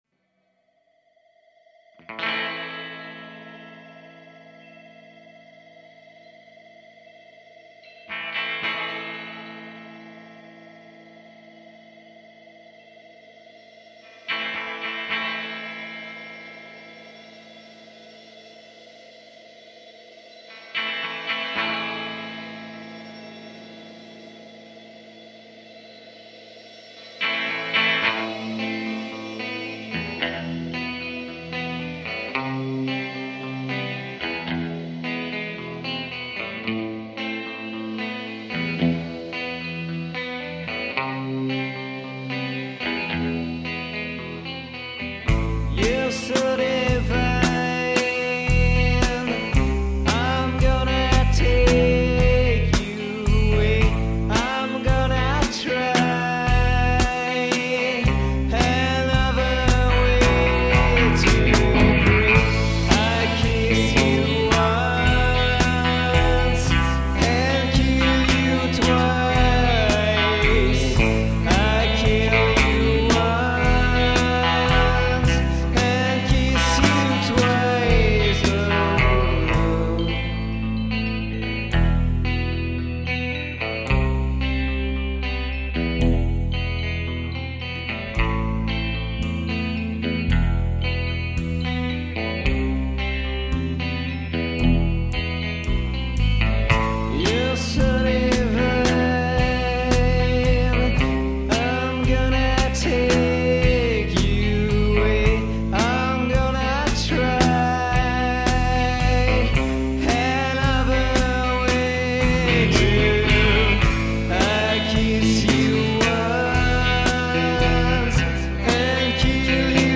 guitare, chant
guitare, clavier
basse
batteur